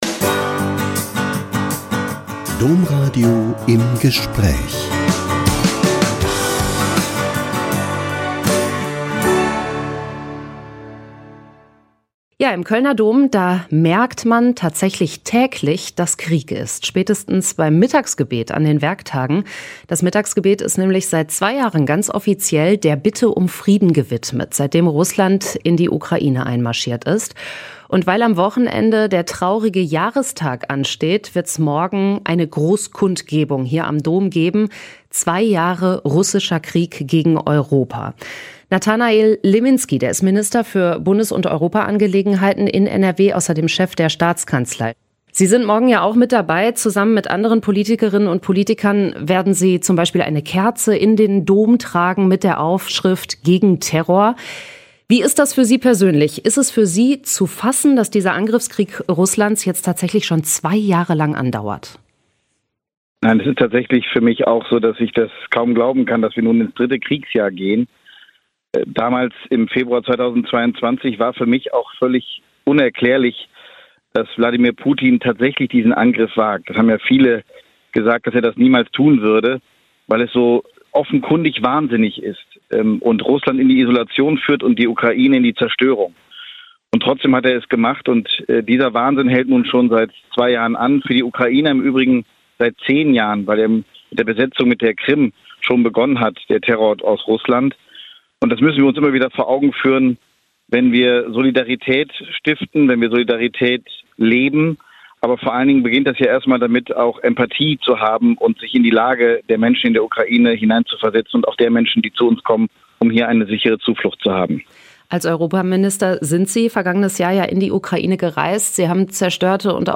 Liminski setzt im Kölner Dom Friedenszeichen für die Ukraine - Gespräch mit Nathanael Liminski (Europaminister und ~ Im Gespräch Podcast